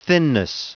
Prononciation du mot thinness en anglais (fichier audio)
Prononciation du mot : thinness